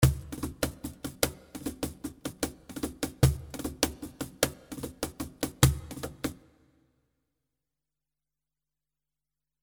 CAJON 75 BPM (5 variations)
This cajon loops made by professional.
Played on lp ( LATIN PERCUSSION ) cajon.
This cajon loops are in 75 bpm playing style called " wahda "